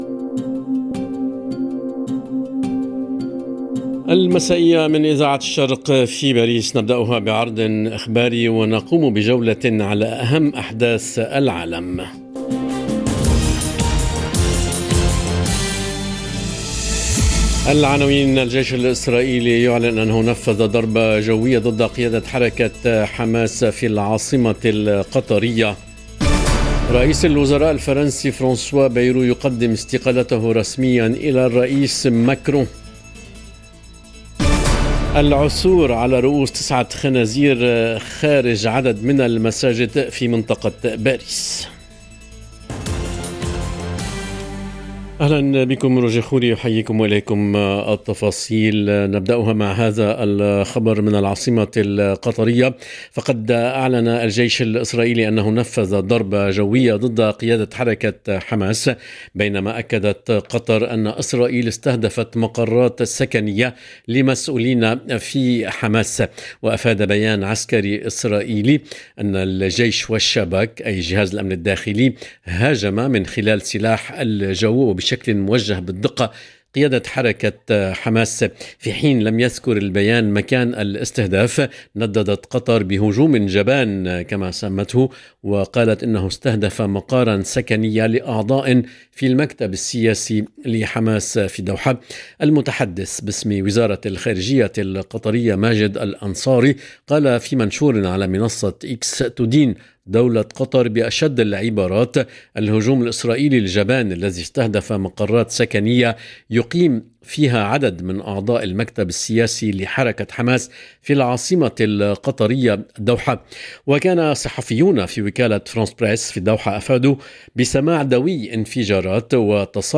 نشرة أخبار المساء: الجيش الإسرائيلي ينفّذ ضربة جوية ضد "قيادة حركة حماس" في العاصمة القطرية الدوحة، وبايرو يقدم استقالته - Radio ORIENT، إذاعة الشرق من باريس